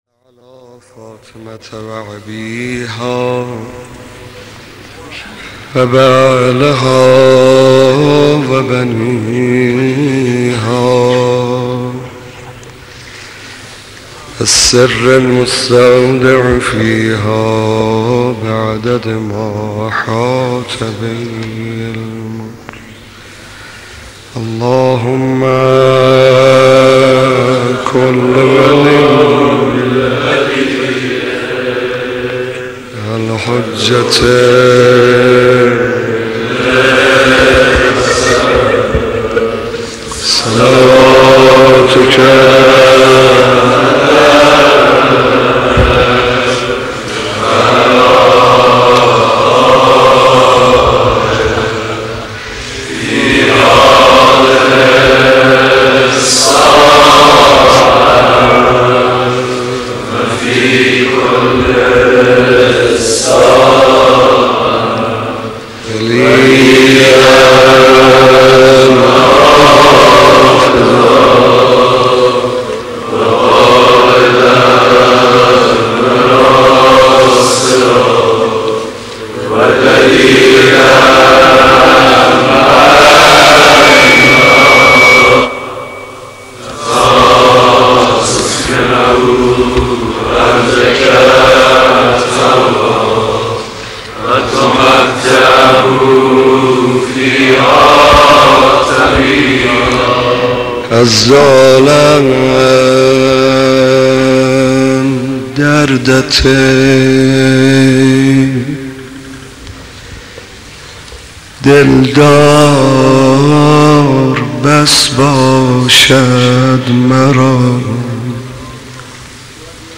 مداح
مناسبت : شب دوم محرم
مداح : محمدرضا طاهری